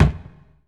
HOLZ       1.wav